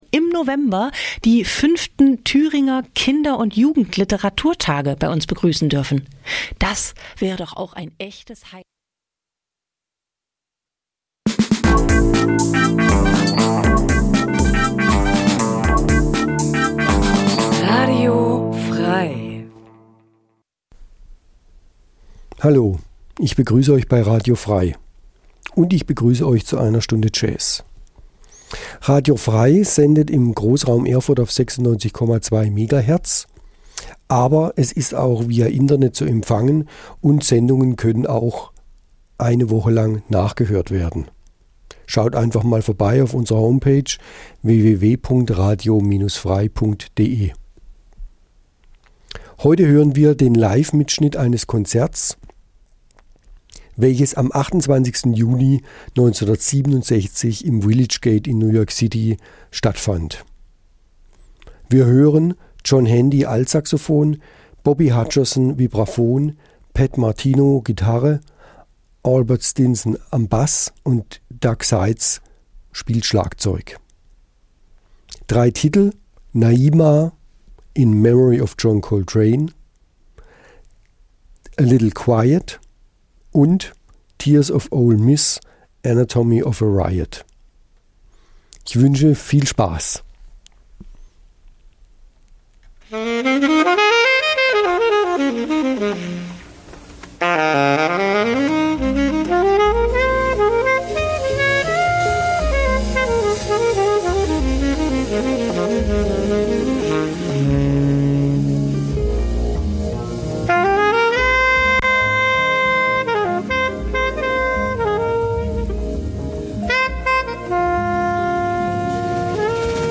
1967 live
Eine Stunde Jazz